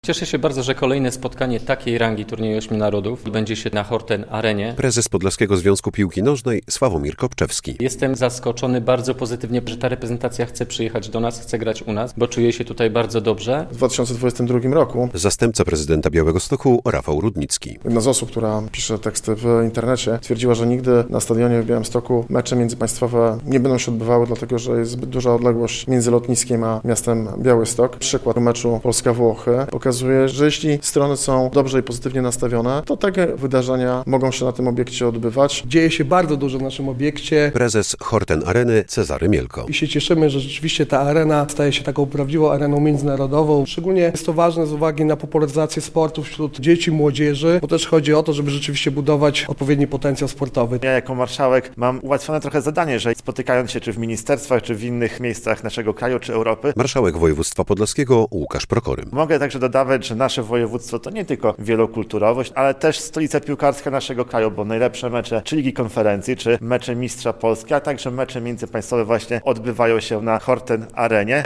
Konferencja prasowa na temat meczu Polska-Włochy - relacja